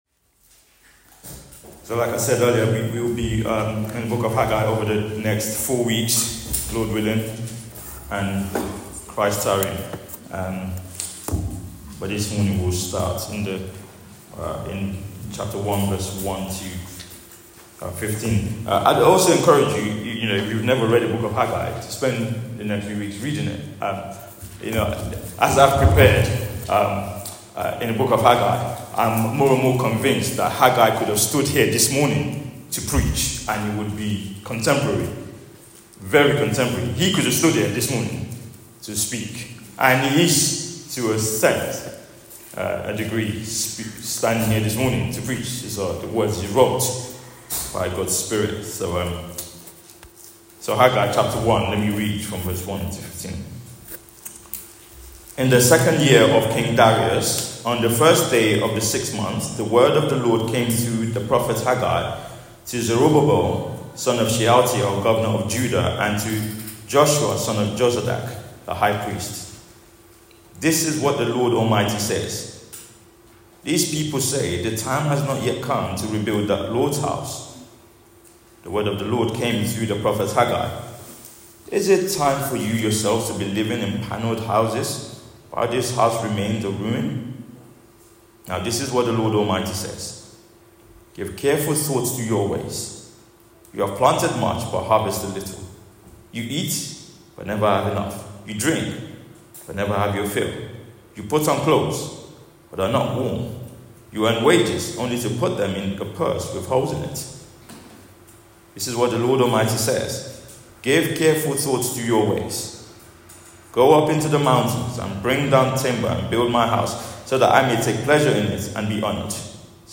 Theme: Building with God's priorities Sermon